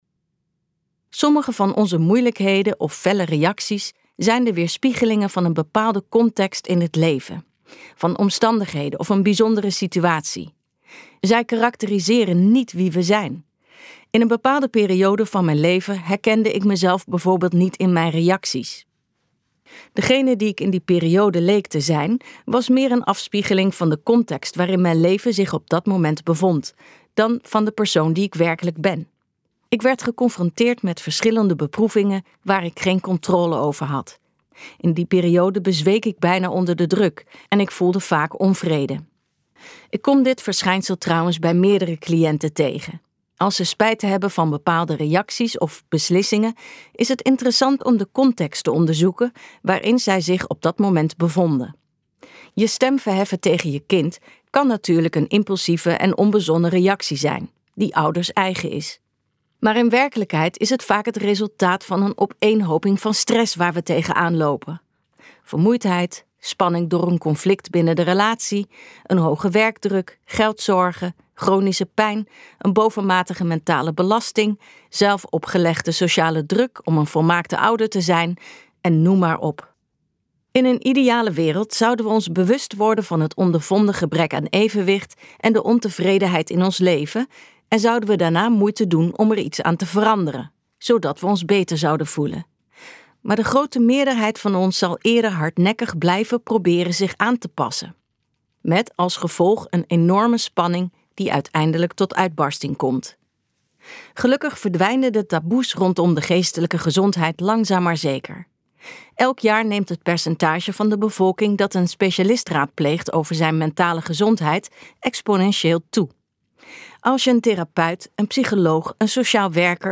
Uitgeverij Ten Have | Terwijl je wacht op de psycholoog luisterboek